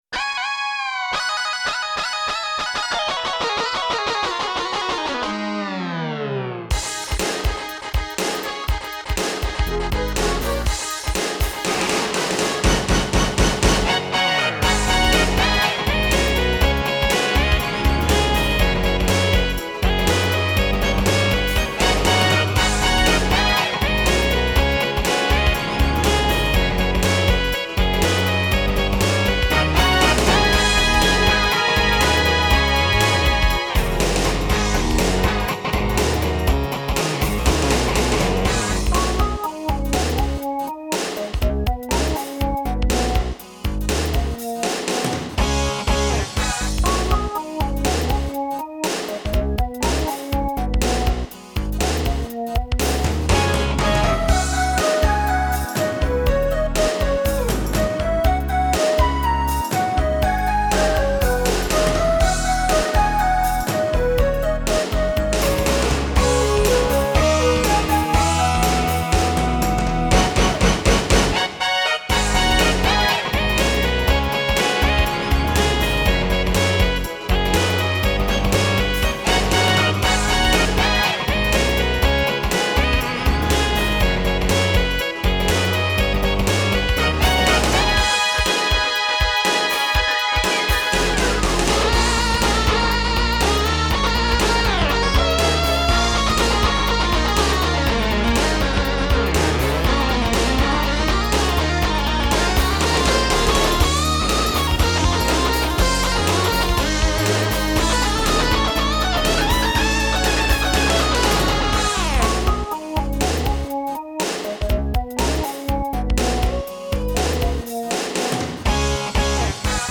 Korg Audio Gallery AG-10
Commercialisé à partir de 1993, le module de sons Korg AG-10 (nommé aussi Audio Gallery) était destiné aux possesseurs de PC & Mac.
L’AG-10 dispose d’une polyphonie de 32 voix, 32 Oscillateurs (Mode Single) ou 16 voix, 32 Oscillateurs (Mode Double).
Seule la norme General MIDI est supportée, par conséquent il n’y a que 128 patches disponibles et 4 kits de percussions.